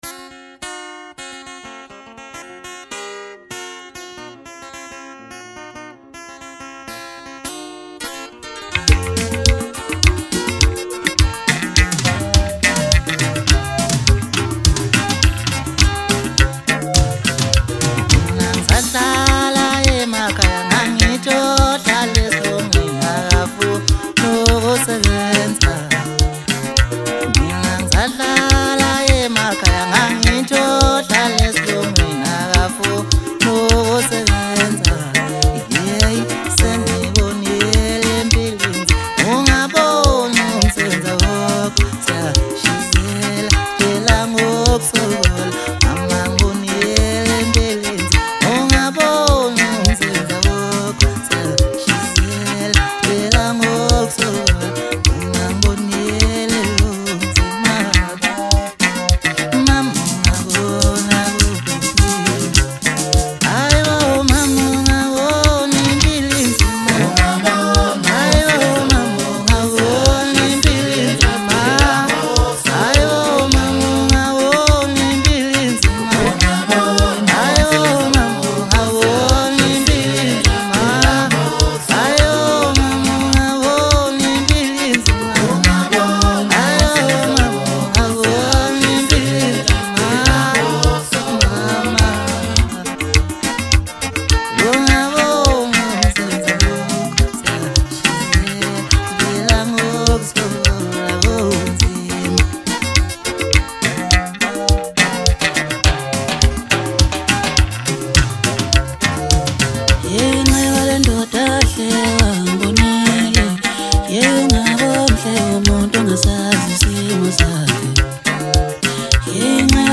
Home » Maskandi » Maskandi Music
Maskandi Songs